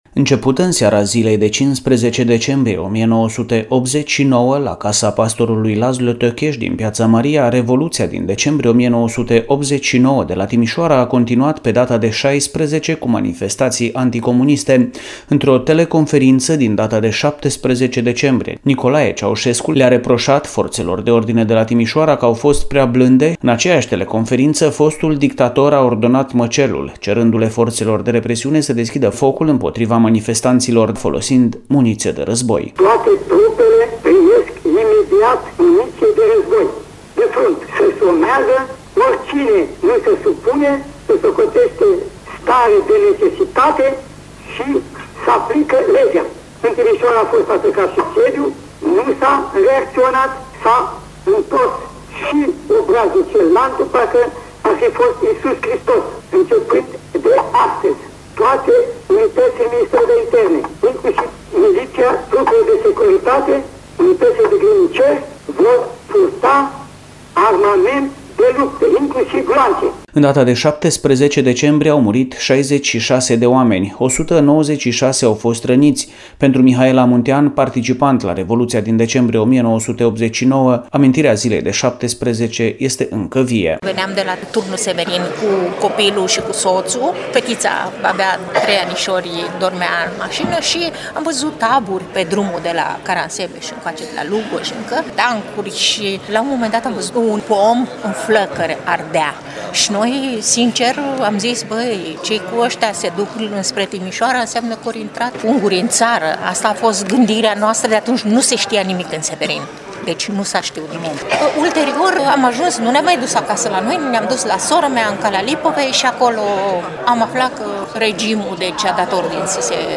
Mărturii din ziua de 17 decembrie